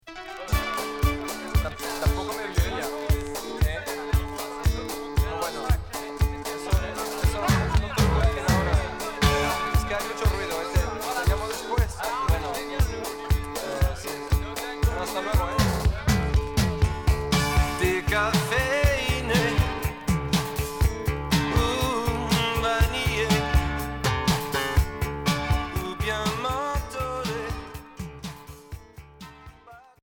Rock new wave Deuxième 45t retour à l'accueil